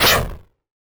claw.wav